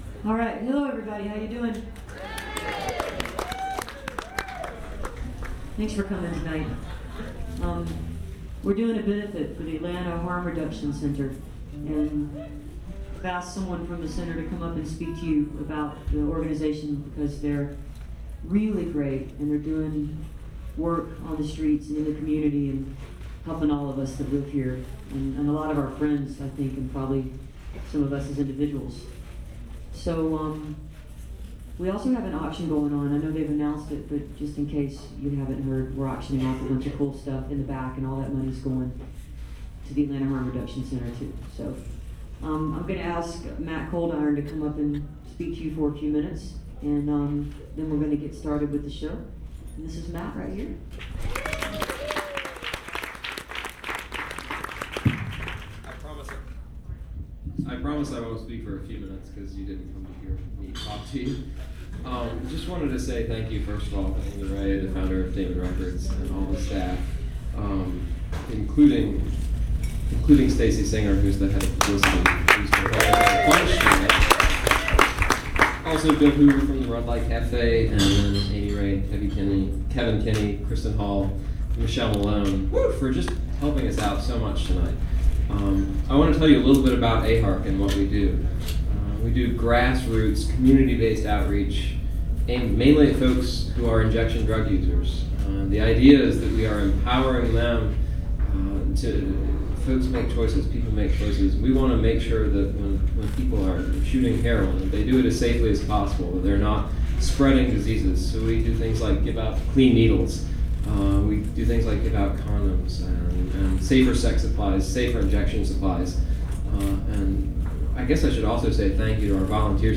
lifeblood: bootlegs: 2003-08-24: red light cafe - atlanta, georgia (atlanta harm reduction center benefit) (amy ray)
01. talking with the crowd